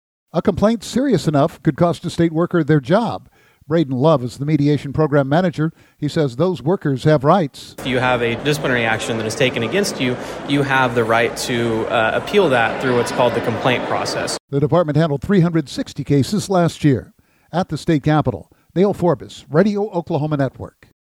details from Radio Oklahoma’s